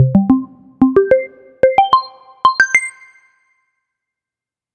Game Sound Intro To Game
FX game intro introduction jingle music sound sound-effects sound effect free sound royalty free Sound Effects